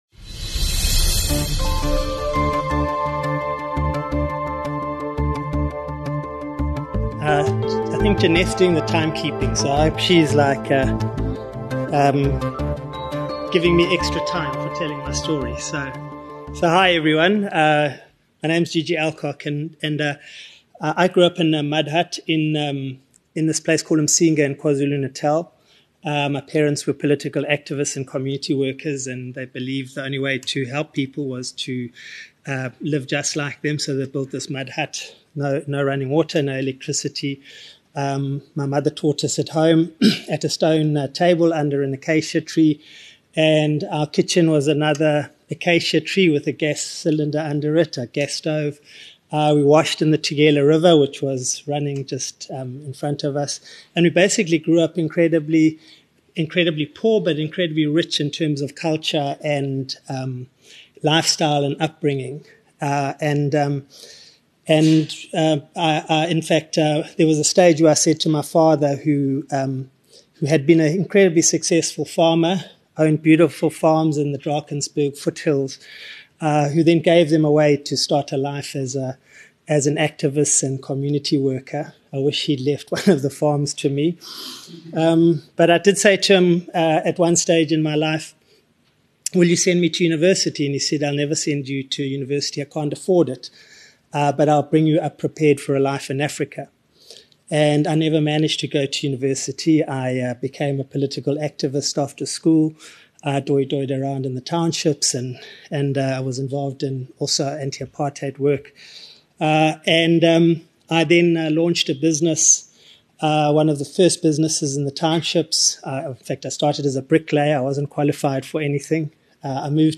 At the BizNews Conference in London